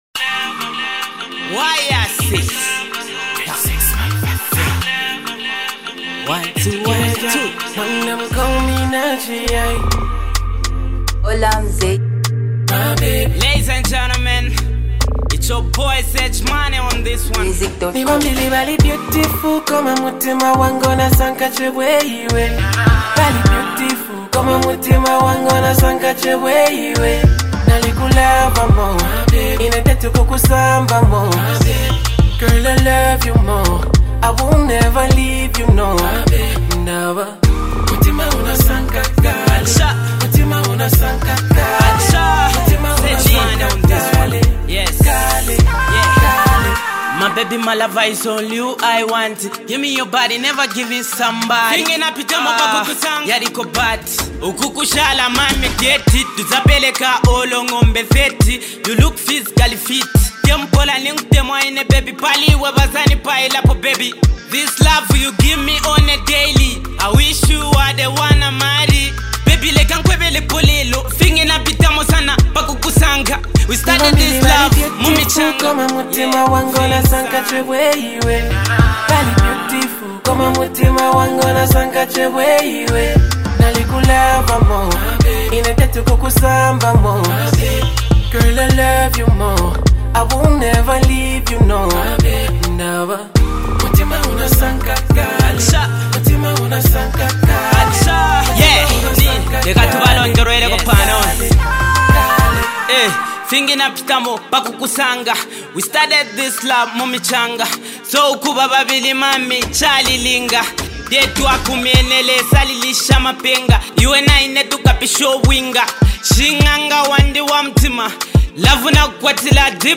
it’s a love song